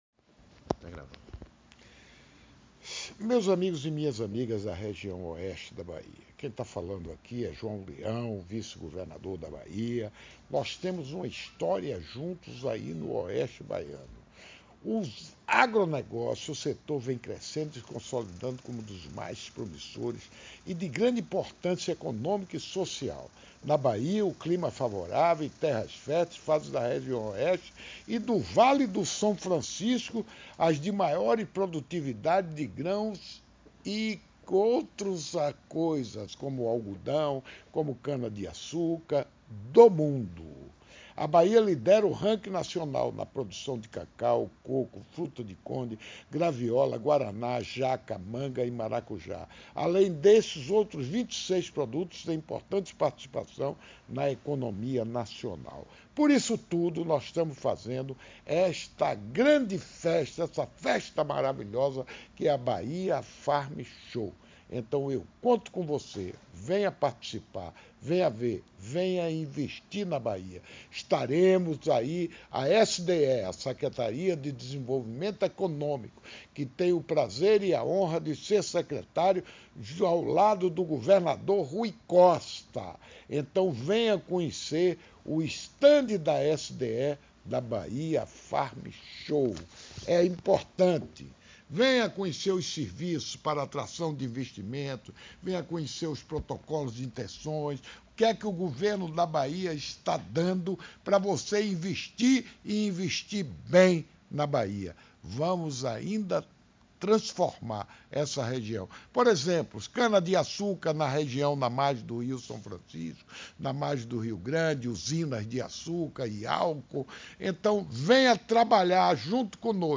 Sonora do vice-governador e secretário de Desenvolvimento Econômico, João Leão, sobre o stand na SDE na Bahia Farm Show 2019